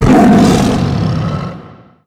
effect_tiger_0003.wav